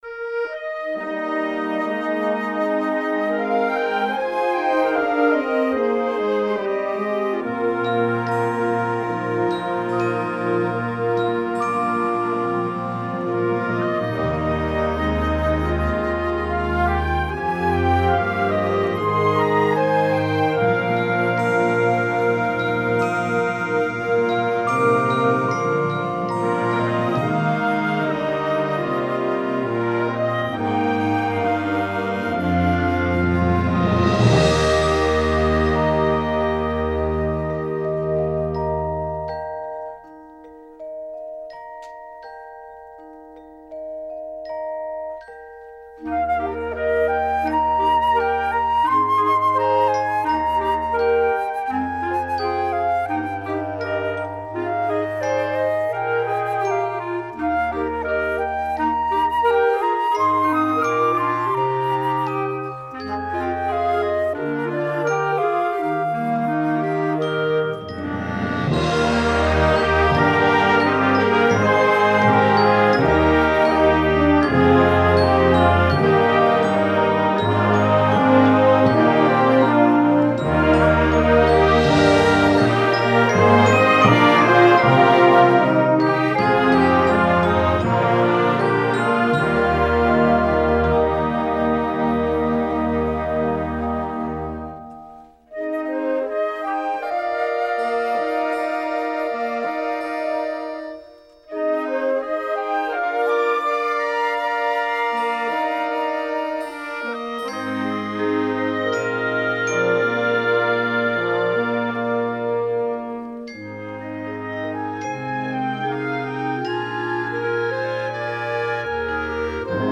Ensemble: Concert Band
Mallet Percussion 2 (Vibraphone, Chimes)
Timpani
Percussion (Suspended Cymbal, Triangle)